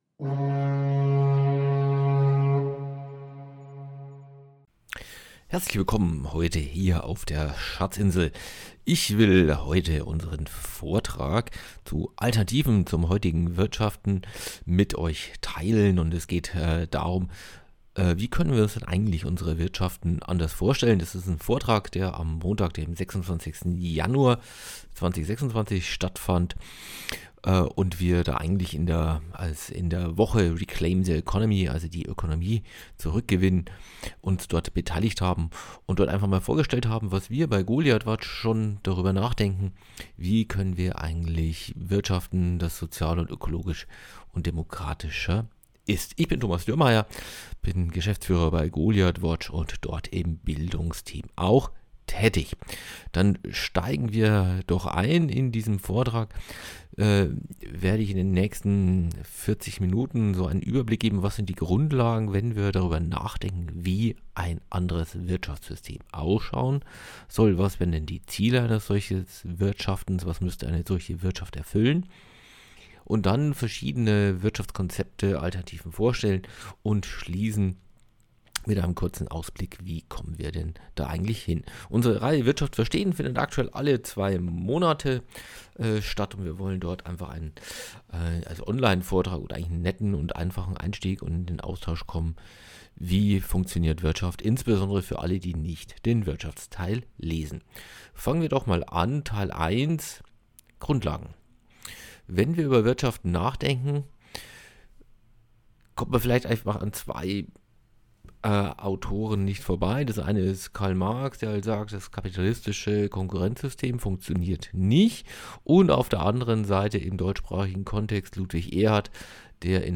Es wird ua. die Doughnut-Ökonomie, Commons und der Neosozialismus vorgestellt. Viele aktuelle Umsetzungserfolge machen die Konzepte deutlich. Die Folge beruht auf den Vortrag der Online-Seminarreihe Wirtschaft verstehen, welche diesmal im Rahmen der internationalen "Reclaim the Economy Week" stattgefunden hat.